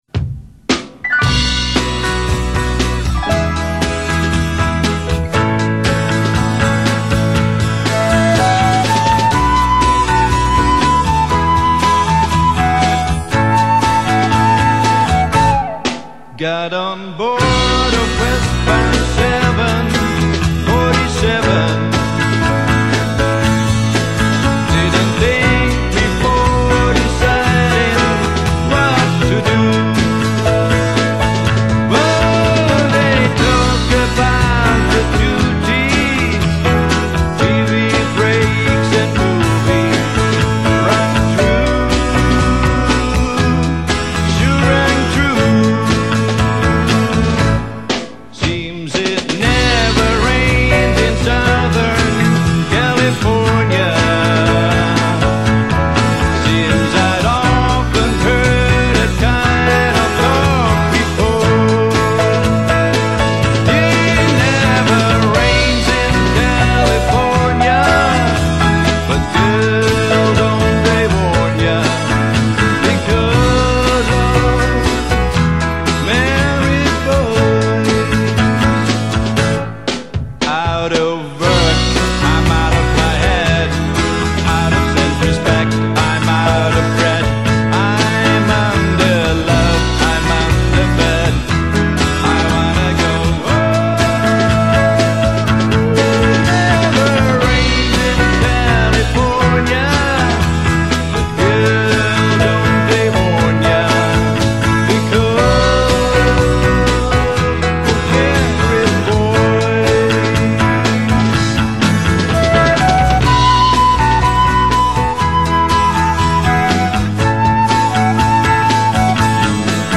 语    种：纯音乐
低吟浅唱，有如天籁，直触人心，荡埃涤尘。木吉他的朴素和弦，民谣歌手的真情演绎，帮我们寻回人类的童真，生活的安宁。